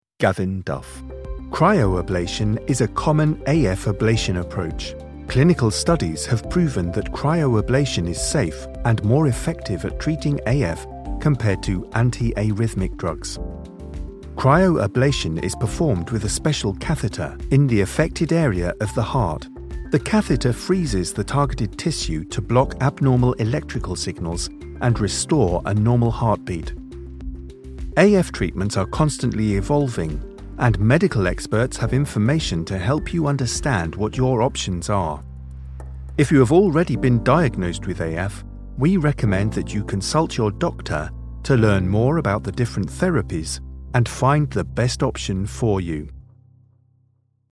Medical Narration
I have a warm and engaging English RP accent which is suitable for a wide range of projects, including audiobook, corporate and commercial.
I produce audio from my purpose built home studio where I use a Shure SM7B mic with a Focusrite Scarlet 2i4 interface and Logic Pro on a Mac.
BaritoneBass